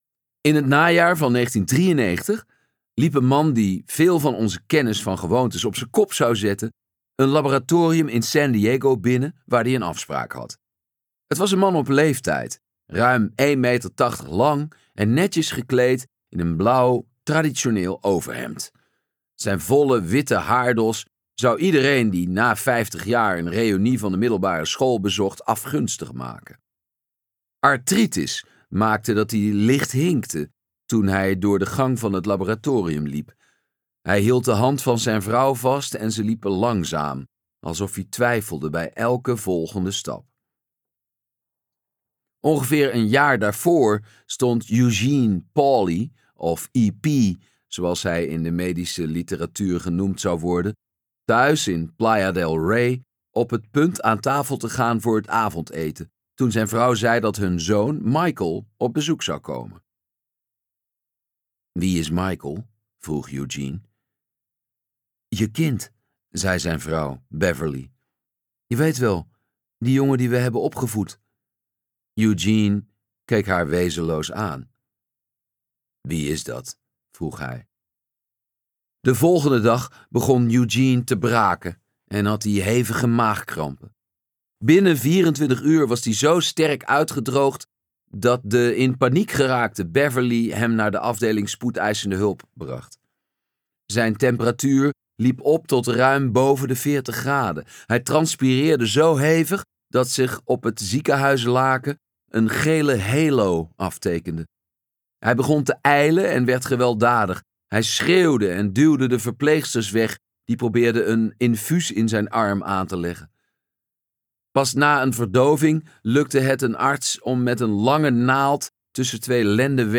Ambo|Anthos uitgevers - Macht der gewoonte luisterboek